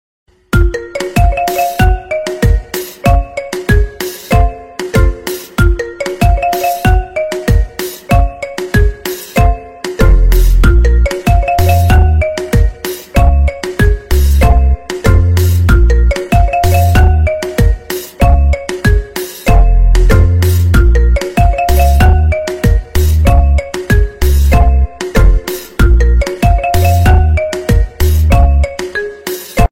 Kategorien Marimba Remix